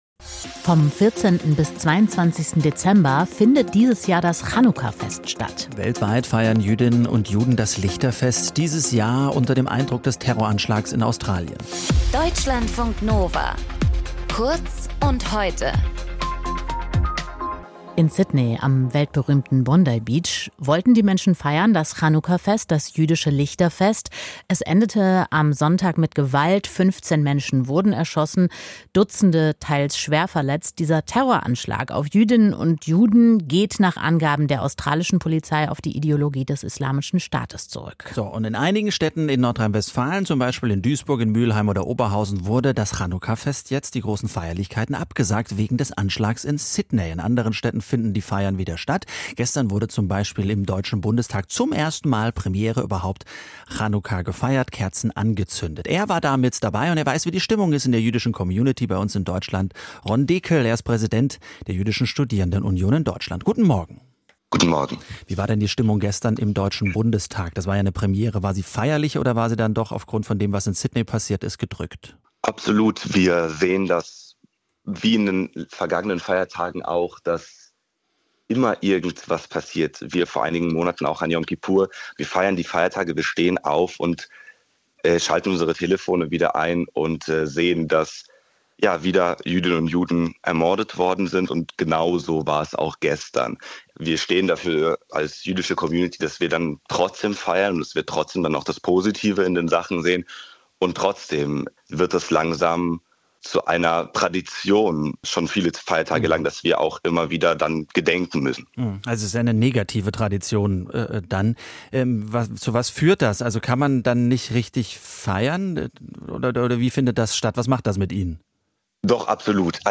In dieser Folge mit: